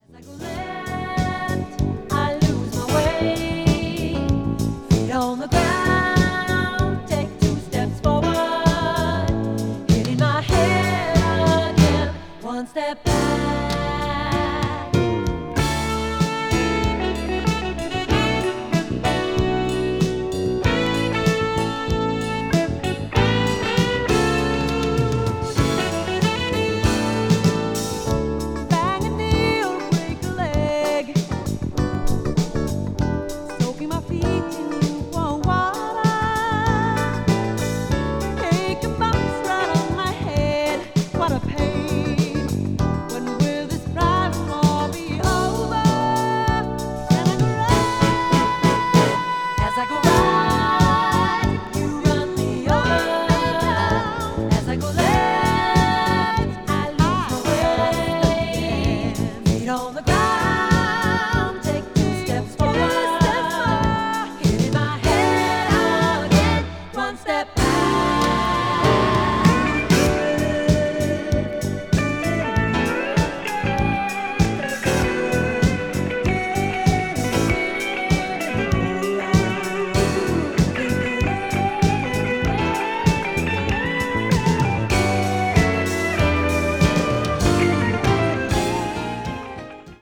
crossover   fusion   jazz groove   mellow groove   pop   r&b